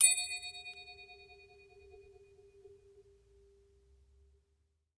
Triangle Medium Strike Spins 1